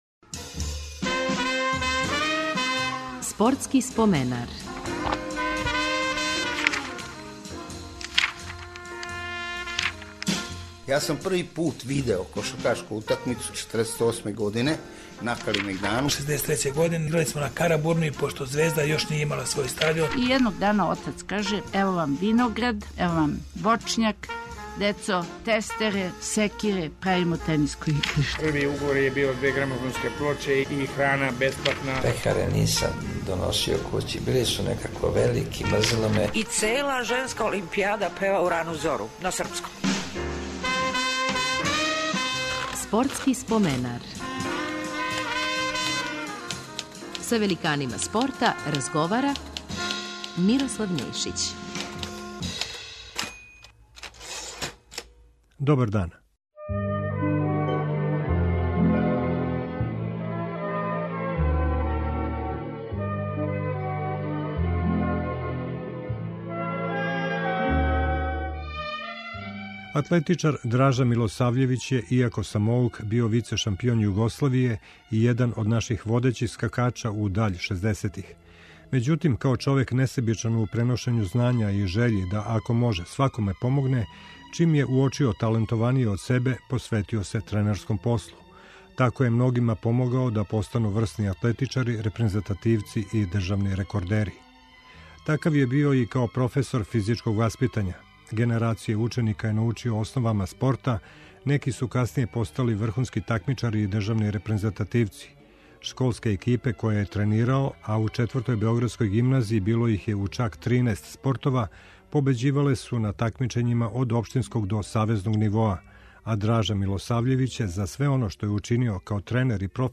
Гост ће бити атлетичар